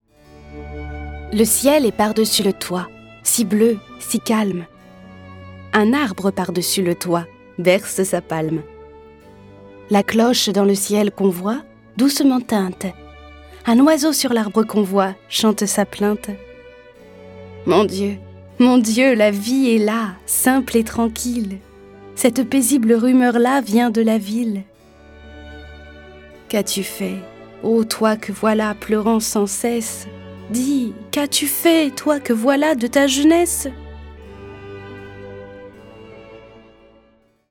Le récit et les dialogues sont illustrés avec les musiques de Bach, Bizet, Boccherini, Bruch, Charpentier, Chopin, Corelli, Debussy, Delibes, Dvorak, Grieg, Haydn, Locatelli, Mozart, Schubert et Tchaïkovski.